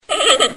Звуки панды
Так говорит одна панда